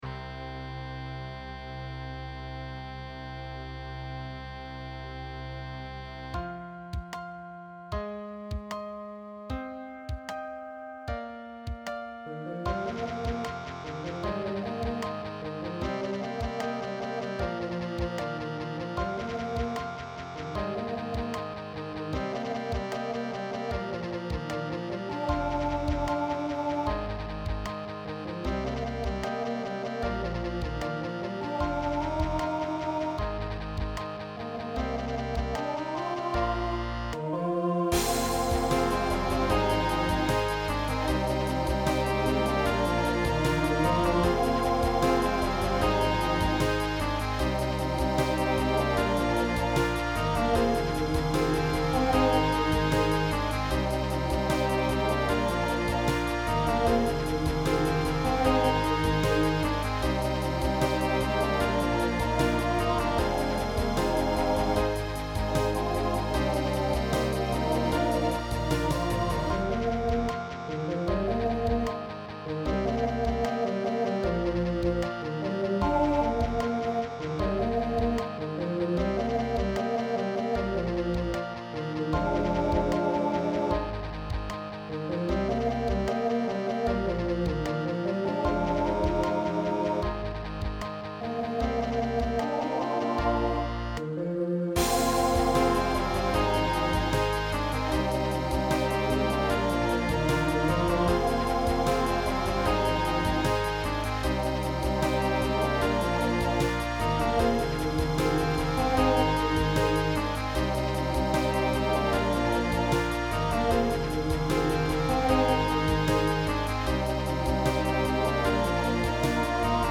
Voicing TTB Instrumental combo Genre Pop/Dance
Mid-tempo